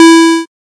safe-1.ogg.mp3